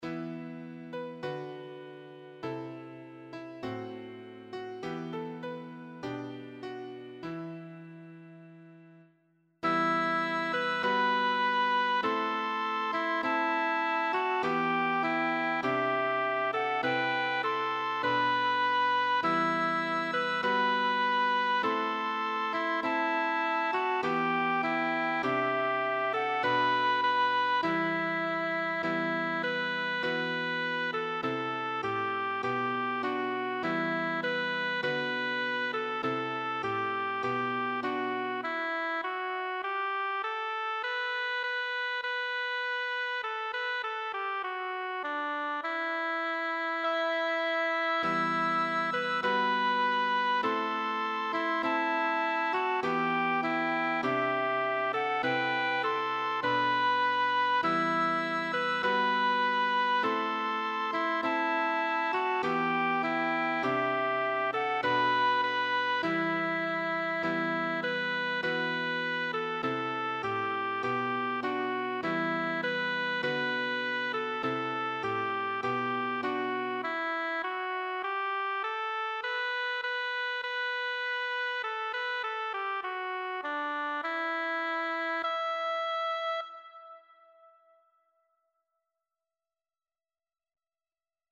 Voz
Abraham-y-El-Yo-Soy-Piano-VOZ.mp3